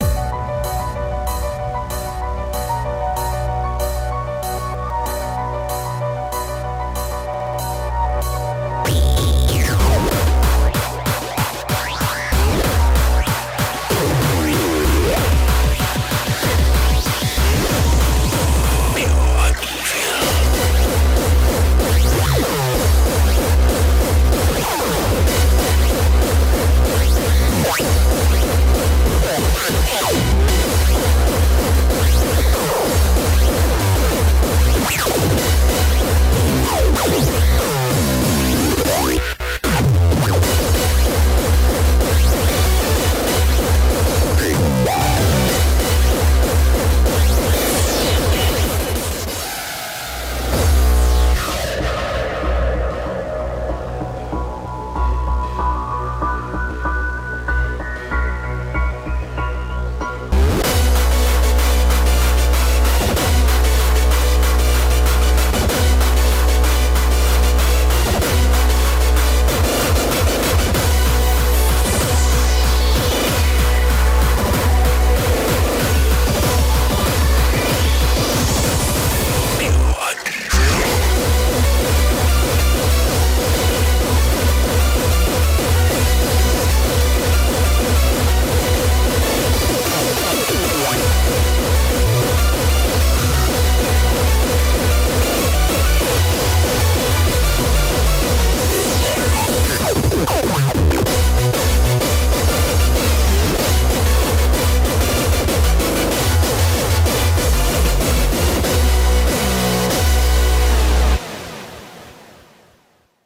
BPM190
Audio QualityLine Out